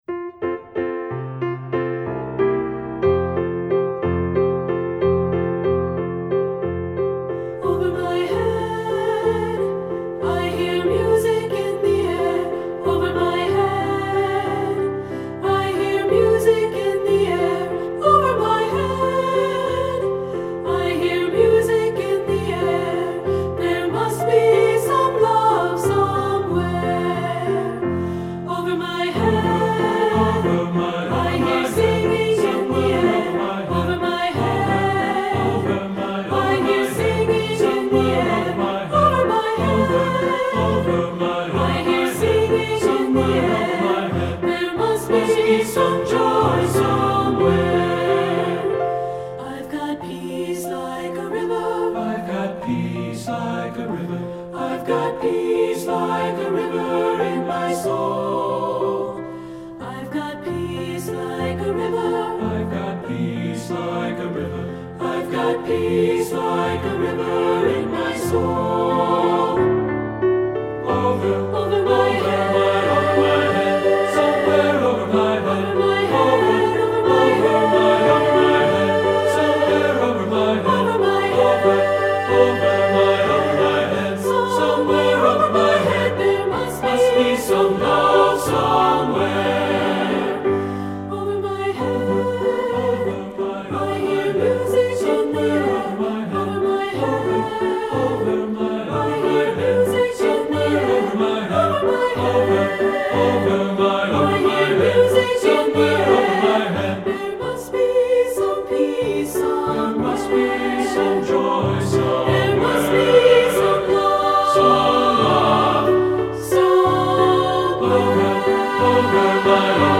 Composer: Traditional Spiritual
Voicing: SATB